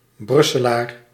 Ääntäminen
Ääntäminen France Tuntematon aksentti: IPA: /bʁyk.sɛ.lwa/ IPA: /bʁy.sɛ.lwa/ Haettu sana löytyi näillä lähdekielillä: ranska Käännös Ääninäyte Adjektiivit 1.